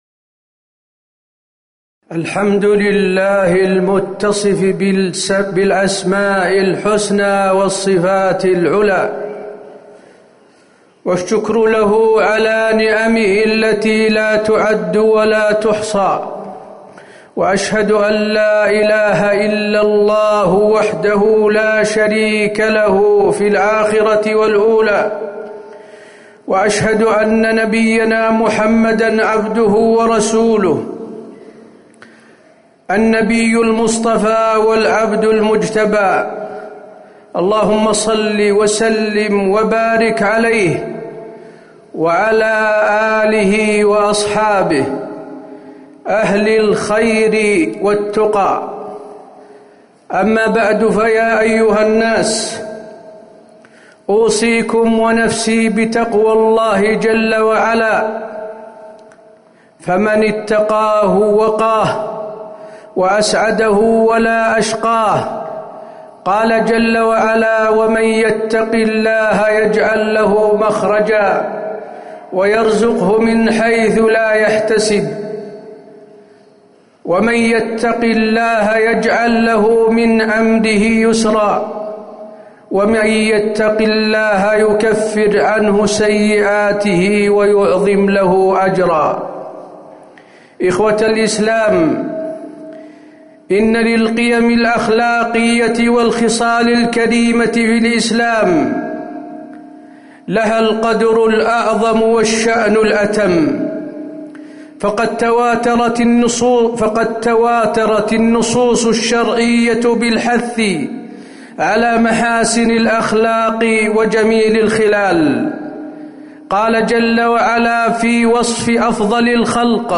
تاريخ النشر ١٩ رمضان ١٤٤٠ هـ المكان: المسجد النبوي الشيخ: فضيلة الشيخ د. حسين بن عبدالعزيز آل الشيخ فضيلة الشيخ د. حسين بن عبدالعزيز آل الشيخ رمضان شهر الرحمة